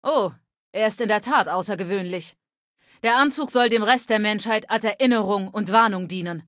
Datei:Femaleadult01default ms02 speechchallengefailur 000b29a7.ogg
Fallout 3: Audiodialoge